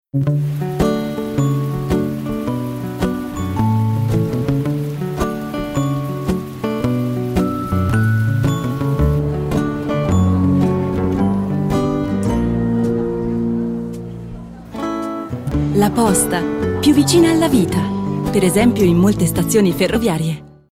Werbung Italienisch (CH)
Ticino